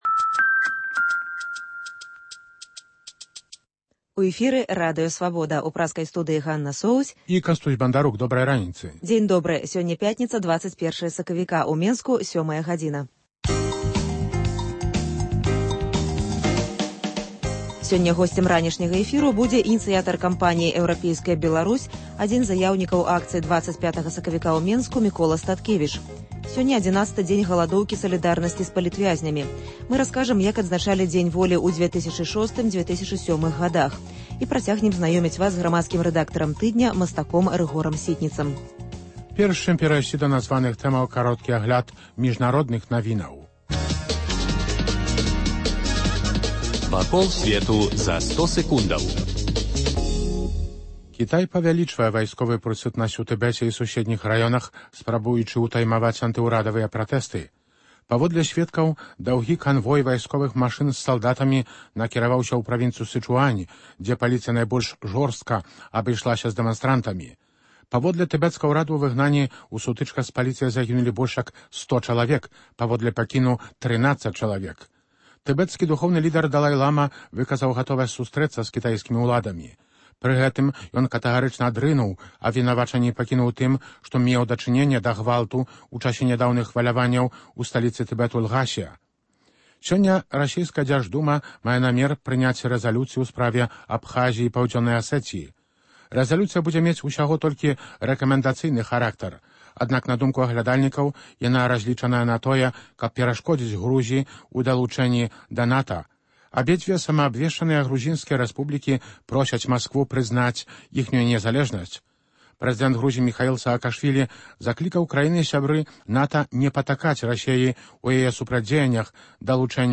Ранішні жывы эфір